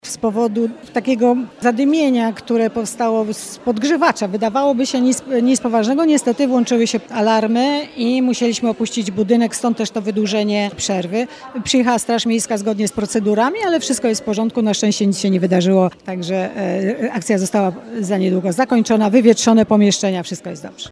Na szczęście nic się nie wydarzyło” – mówi Wioleta Sawicka, Przewodnicząca Rady Miejskiej w Stargardzie.